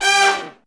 G4 POP FALL.wav